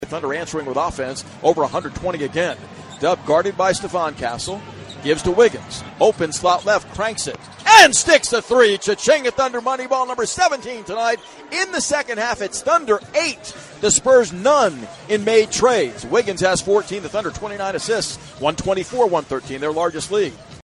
Thunder PBP 3-3.mp3